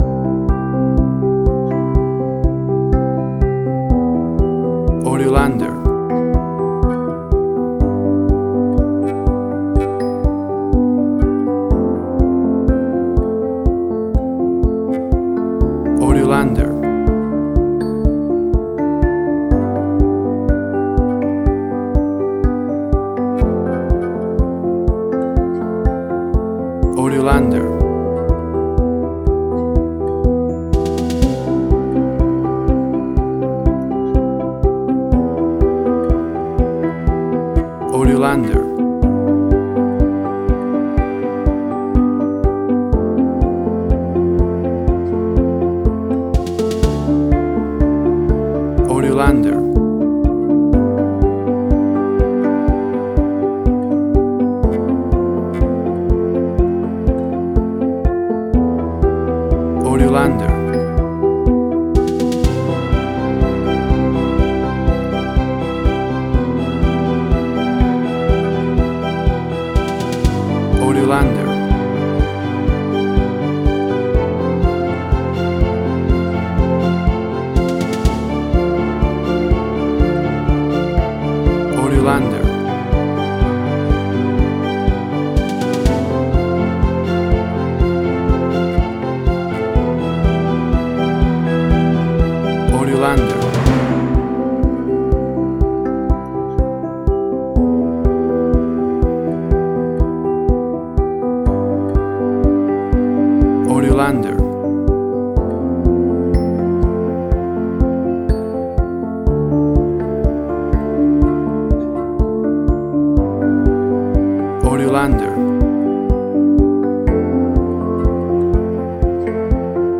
Motivating, hopeful, full of life, optimistic. advertising.
Tempo (BPM): 123